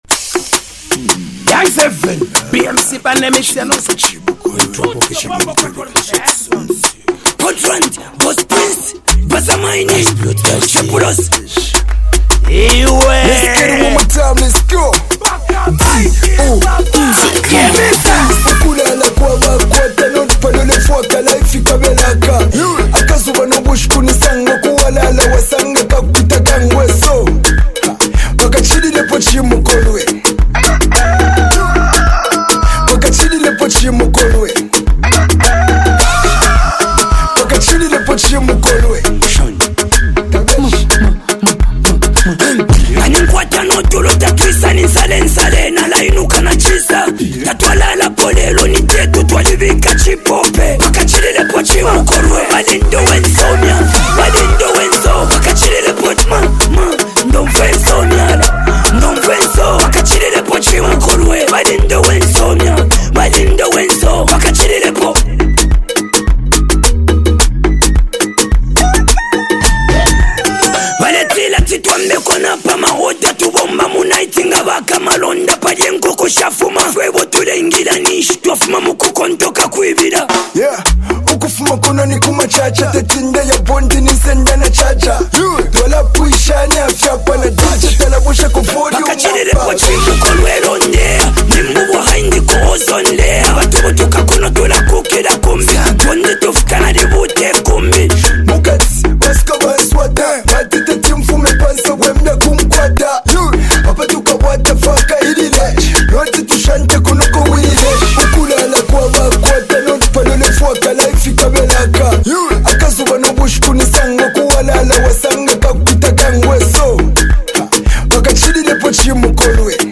rap duo